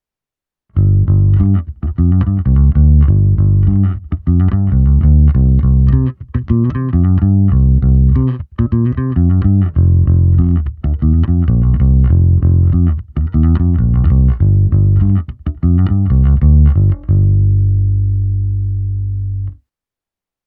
Zvukově je to klasický Precision, i s hlazenkami hraje naprosto parádně, a oproti mnoha jiným Precisionům má tenhle opravdu pevné, vrčivé Éčko.
Stejné kolečko jako ukázka 2, ale už s kompresorem, ekvalizací a simulací aparátu:
Ukázka 4 - simulace zesilovače Ampeg SVT a boxu Ampeg 8×10"